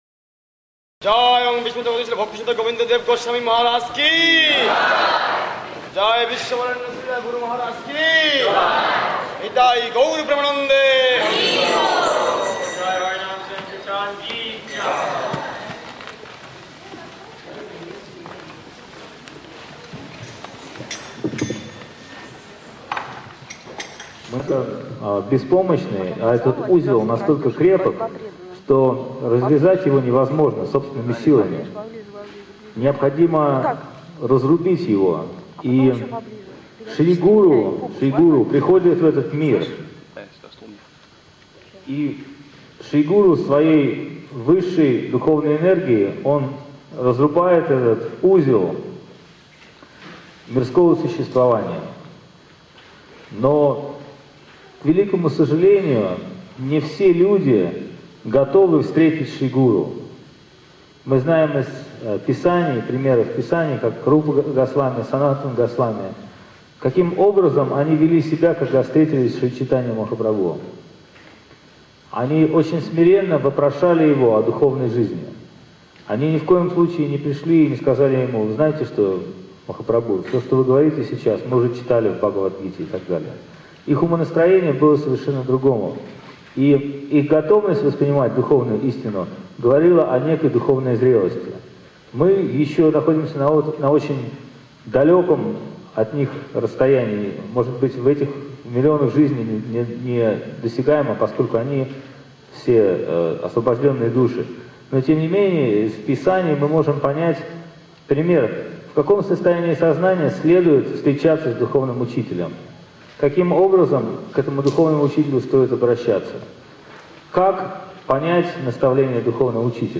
Баджан Радха-Мадхава.